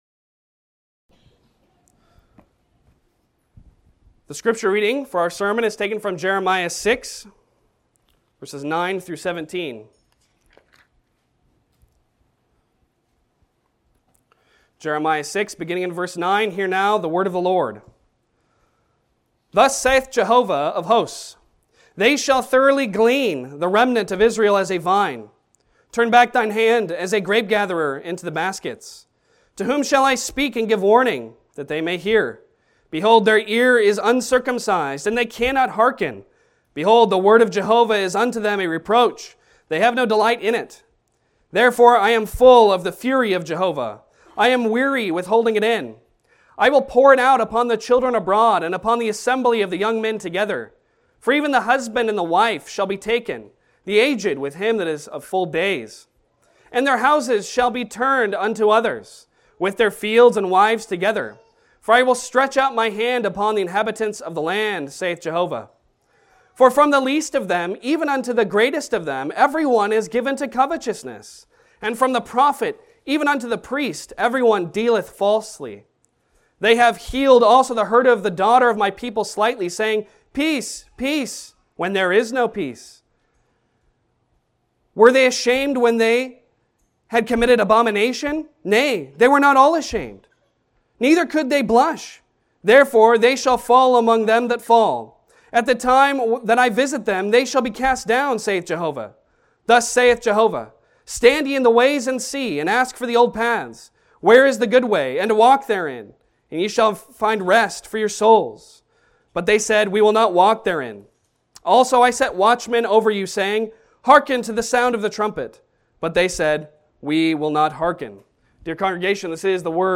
Jeremiah 6:9-17 Service Type: Sunday Sermon Download Files Bulletin « Christ